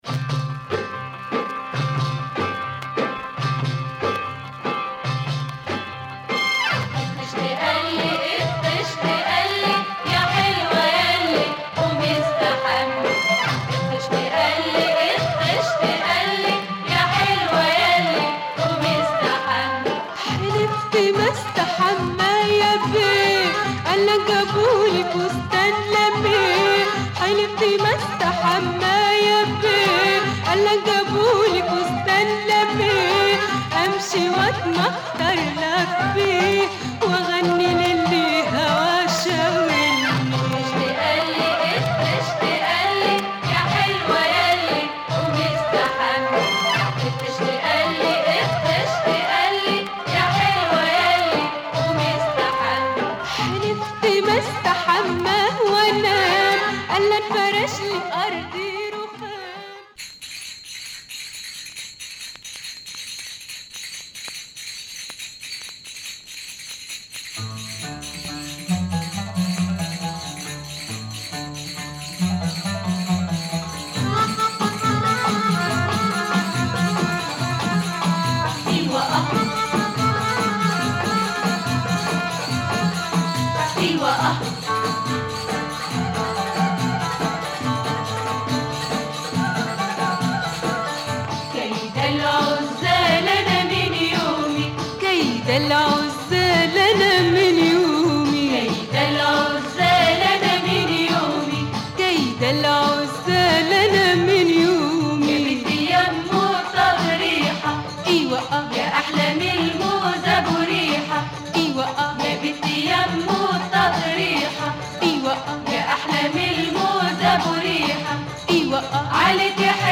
Super rare Egyptian soundtrack EP